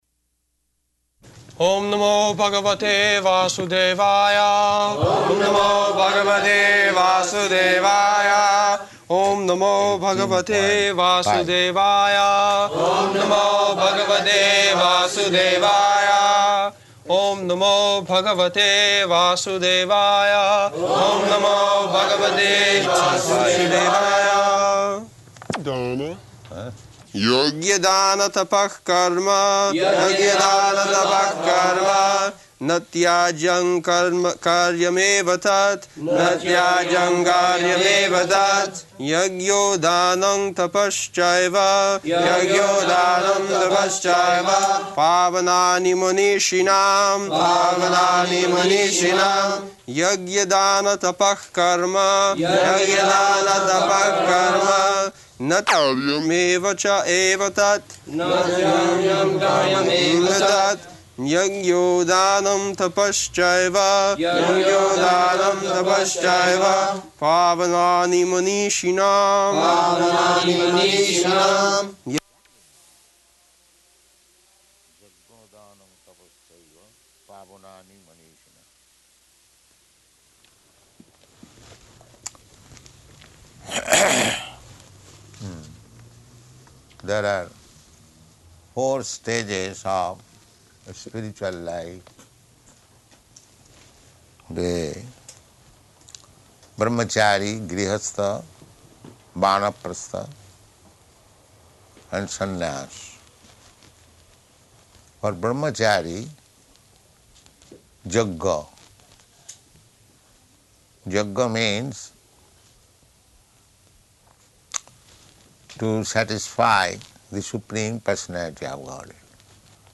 -- Type: Bhagavad-gita Dated: September 5th 1973 Location: London Audio file
[break] [leads chanting of verse, etc.] [Prabhupāda and devotees repeat] yajña-dāna-tapaḥ-karma na tyājyaṁ kāryam eva tat yajño dānaṁ tapaś caiva pāvanāni manīṣiṇām [ Bg. 18.5 ] [break] [01:15] Prabhupāda: ...yajño dānaṁ tapaś caiva pāvanāni manīṣiṇām.
[sounds of Prabhupāda's sweater being removed] [aside:] Hmm.